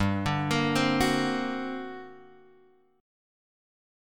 GmM11 chord {3 x 0 3 1 2} chord